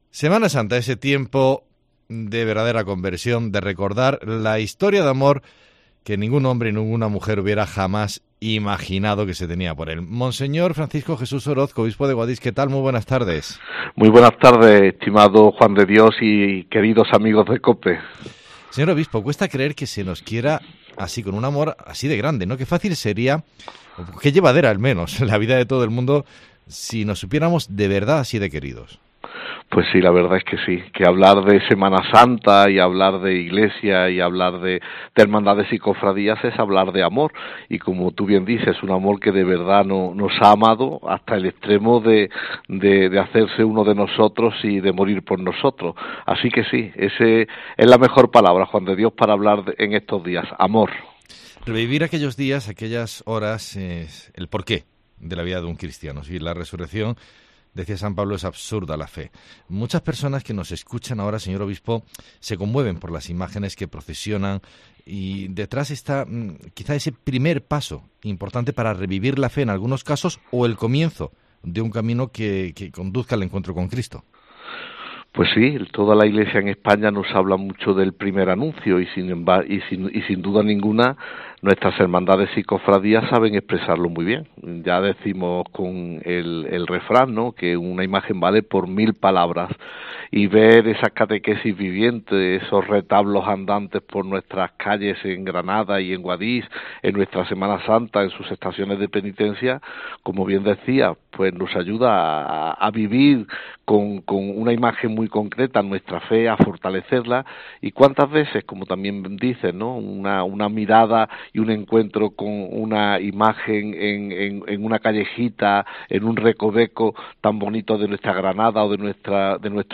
AUDIO: El obispo de Guadix habla sobre la Cuaresma, la Pasión y Resurrección de Jesús, y la importancia de las hermandades en la vida de la Iglesia.